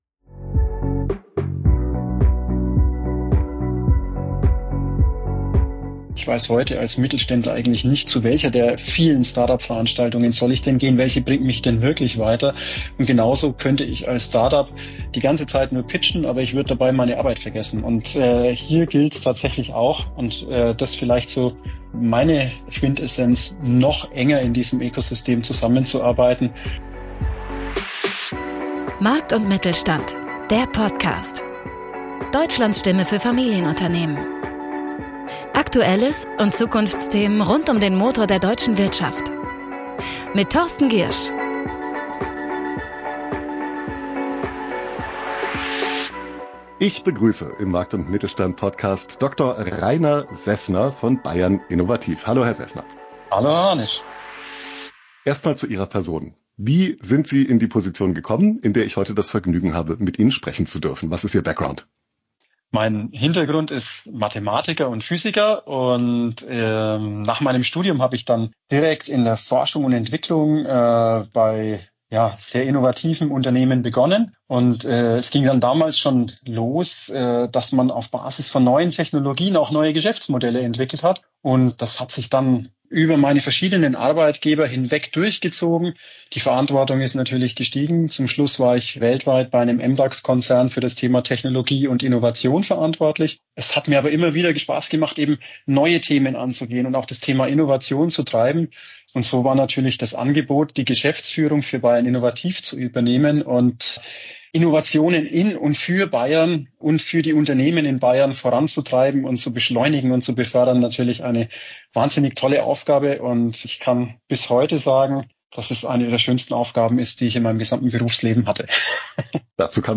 Wir sprechen mit einem Gast zu einem für den Mittelstand wichtigen Thema – hoher Nutzwert garantiert! Unser Ziel ist, (potenzielle) Führungskräfte in mittelständischen Unternehmen auf Ideen zu bringen, wie sie ihr Unternehmen zukunftsfester machen können.